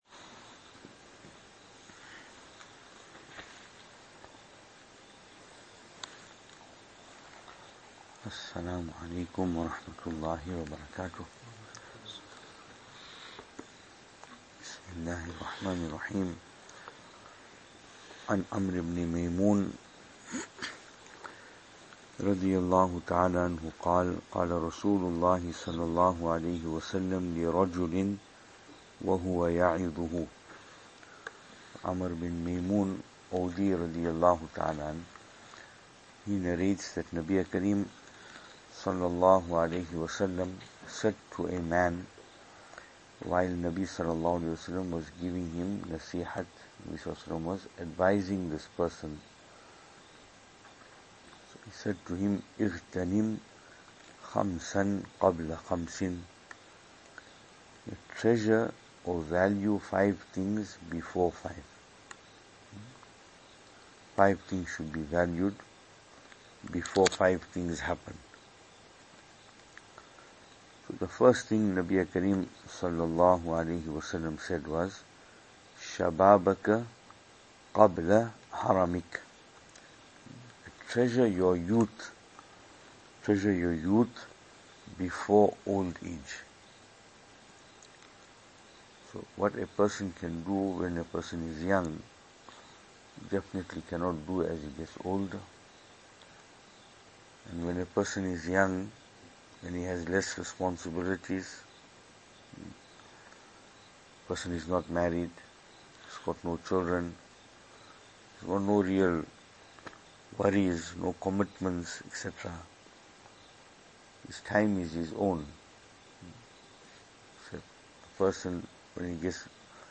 After Taraweeh Advices 28th Night
2023-04-19 After Taraweeh Advices 28th Night Venue: Albert Falls , Madressa Isha'atul Haq Series